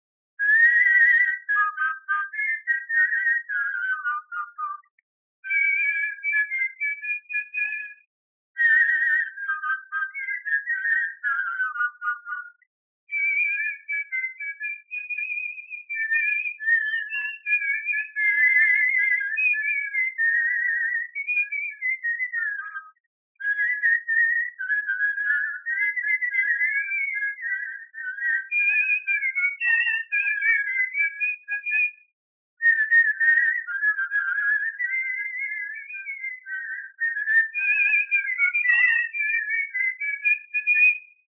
Theme: Instrumental and instrumental-sung music
Locality: Magyarcsanád/Čanad
whistling
Comment: Melody of the song was created by whistling. Accompanyment of the melody in parallel thirds in such performance is an indication of deep root of this chord in musical style of South Slavs in these regions.